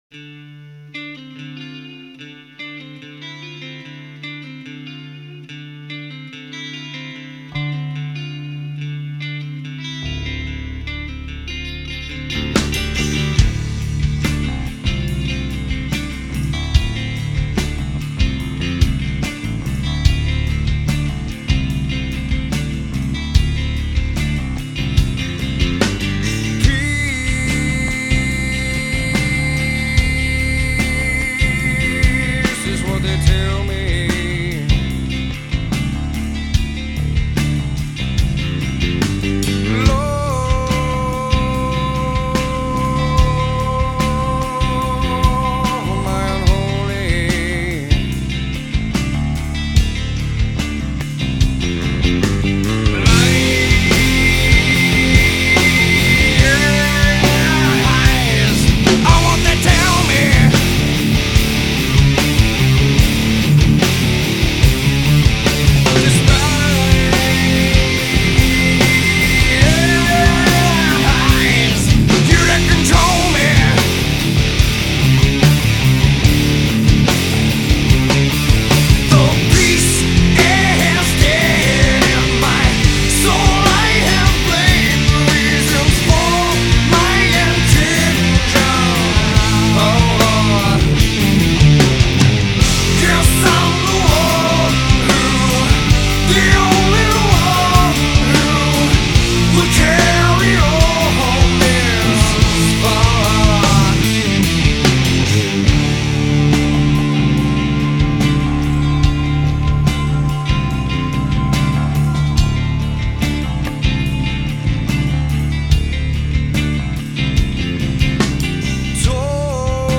Post-Grunge, Alternative Rock